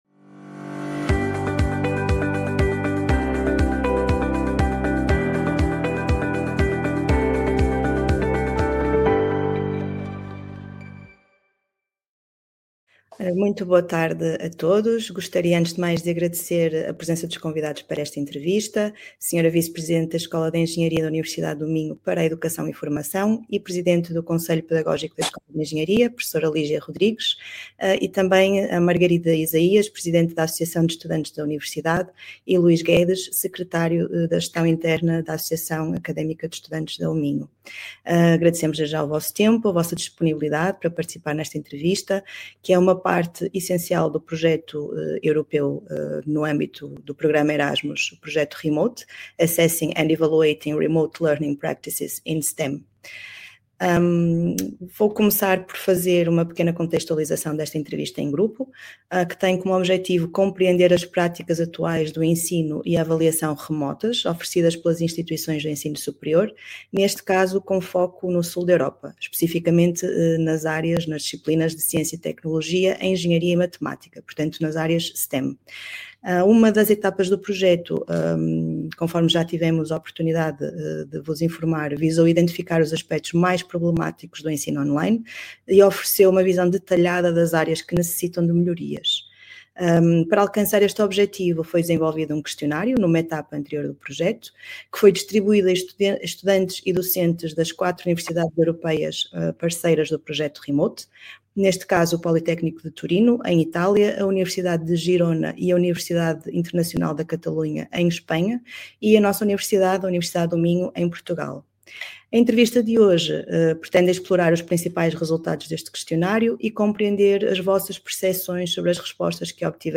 In-Depth Interviews
Entrevista-conversa